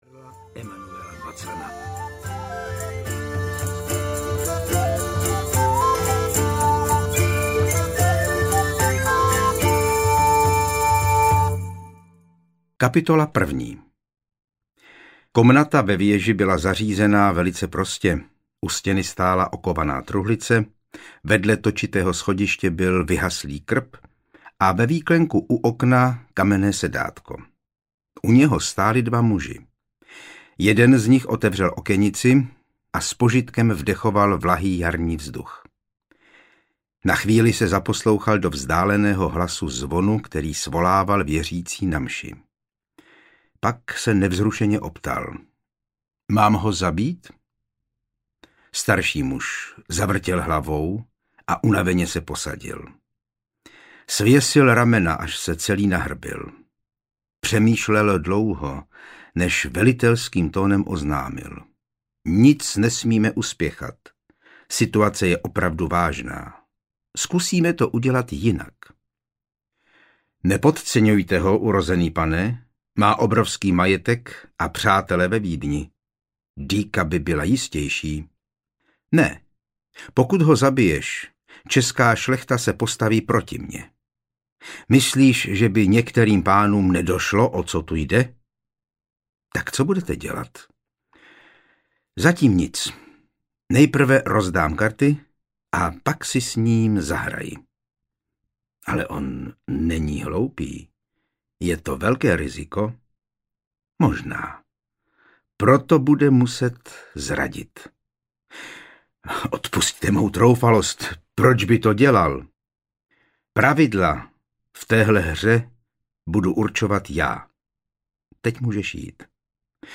Tichý jazyk audiokniha
Ukázka z knihy